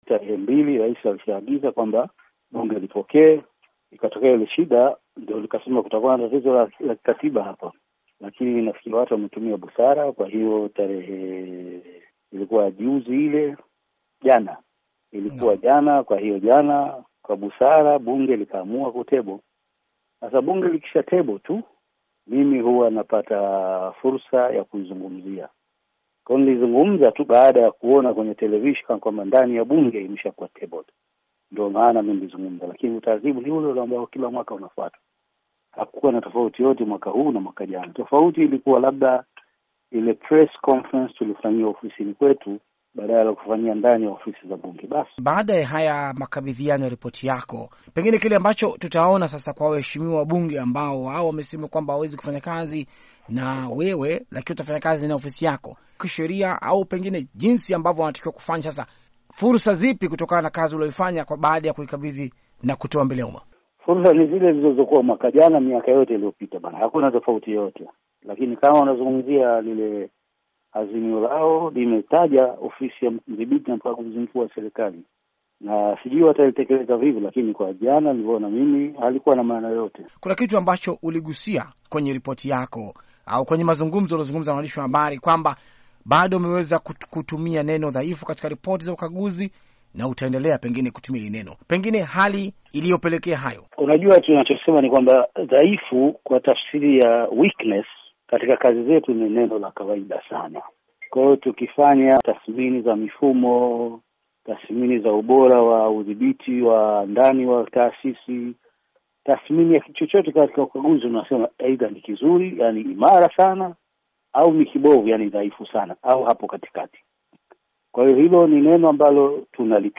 Mahojiano maalum na CAG